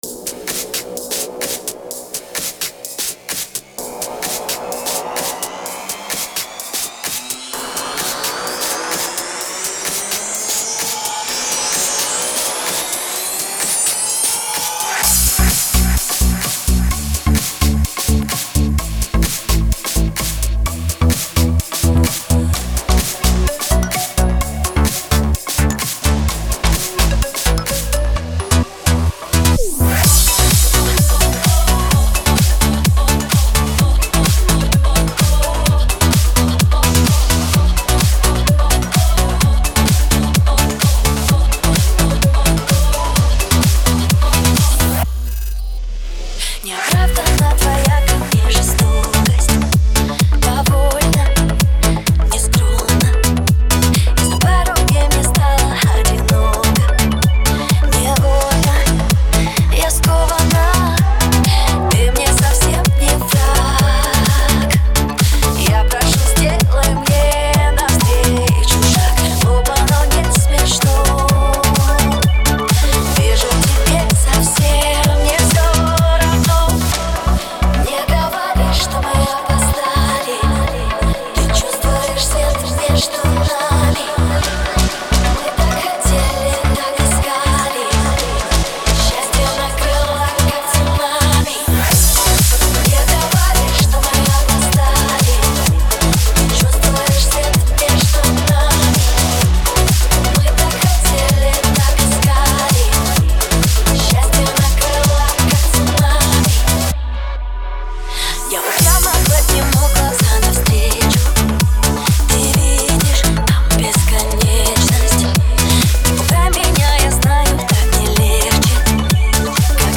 Категория: Ремиксы